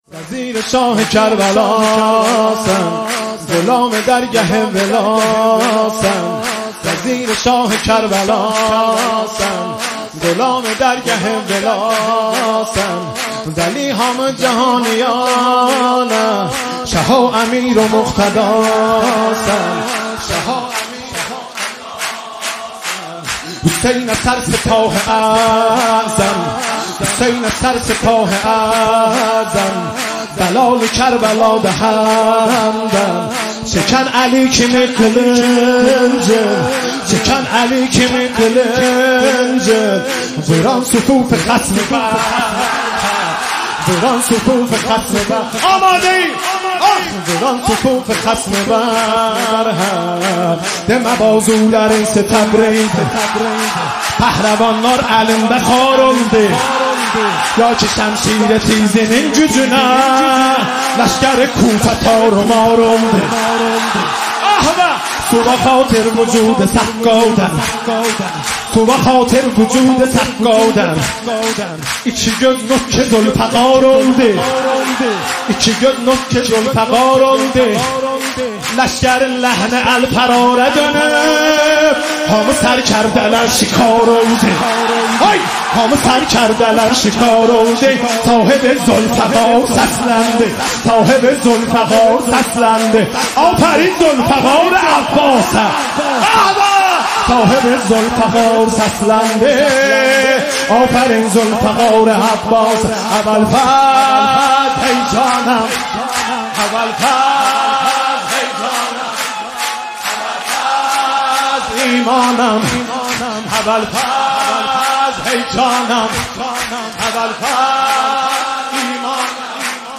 مولودی آذری